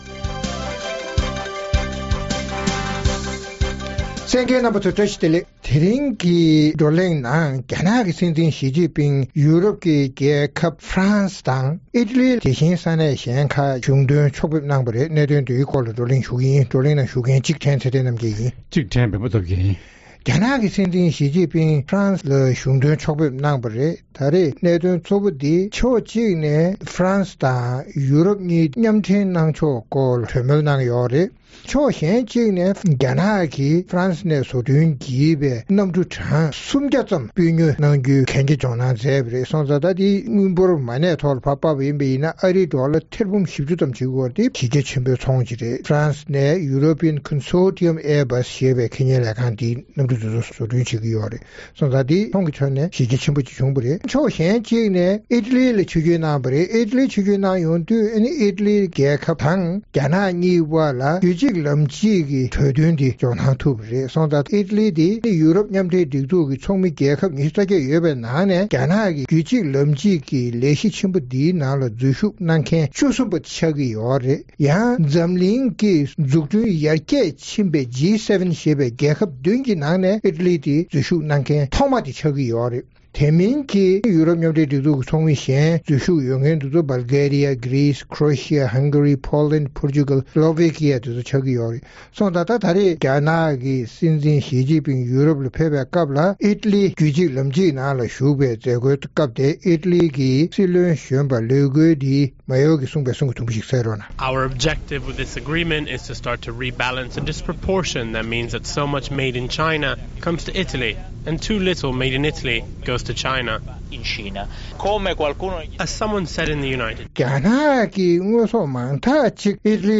རྩོམ་སྒྲིག་པའི་གླེང་སྟེགས་ཞེས་པའི་ལེ་ཚན་ནང་། རྒྱ་ནག་གི་སྲིད་འཛིན་ཞི་ཅིན་ཕིང་ཡོ་རོབ་ཀྱི་རྒྱལ་ཁབ་France དང་Italy བཅས་སུ་གཞུང་དོན་ཕྱོགས་ཕེབས་གནང་སྟེ་ཚོང་དོན་གཙོ་གྱུར་གྱིས་ཚོང་ལམ་མཉམ་སྦྲེལ་བྱ་ཕྱོགས་ཀྱི་གྲོས་མཐུན་བཞག་པ་སོགས་ཀྱི་སྐོར་རྩོམ་སྒྲིག་འགན་འཛིན་རྣམ་པས་བགྲོ་གླེང་གནང་བ་གསན་རོགས་གནང་།